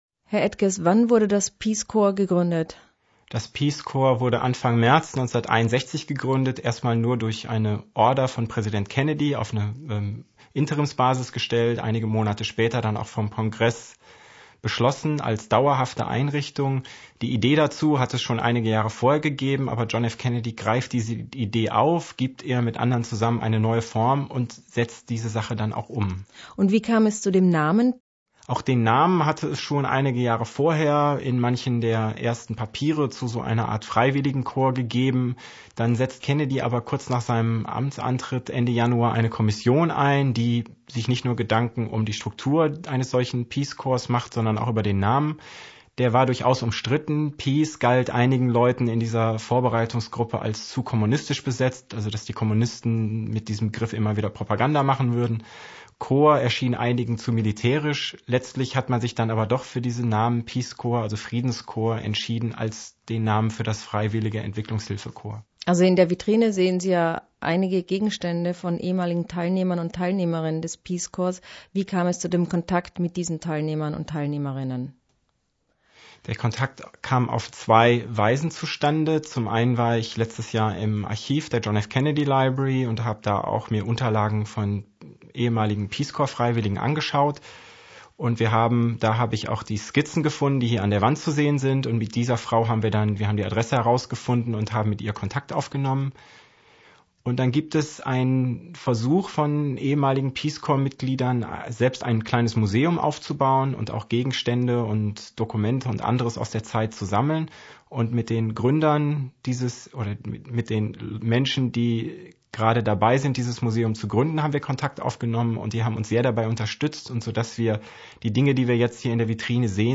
Interview mit dem Kurator der Ausstellung